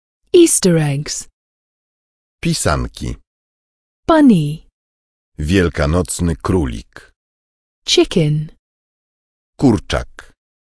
- płyta CD z nagraniami słówek i piosenek.
Znajdują się na niej wszystkie słówka i piosenki udźwiękowione przez profesjonalnych lektorów.